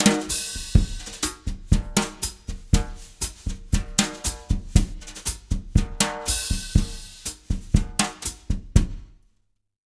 120BOSSA05-R.wav